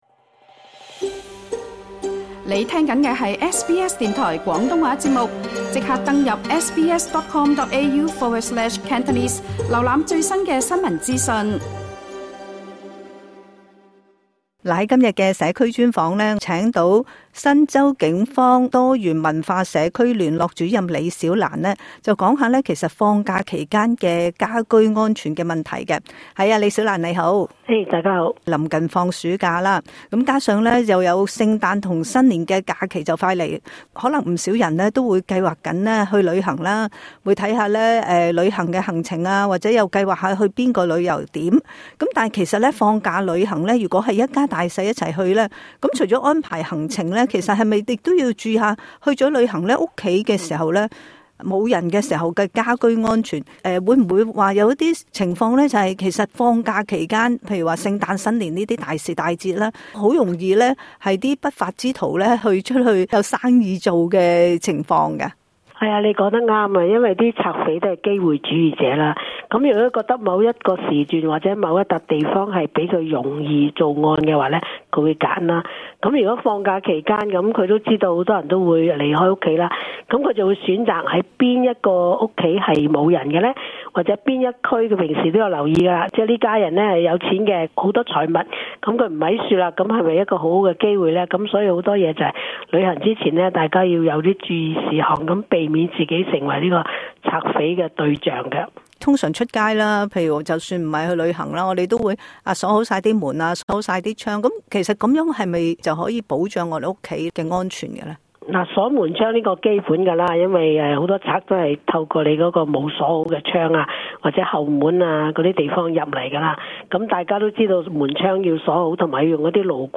今集【社區專訪】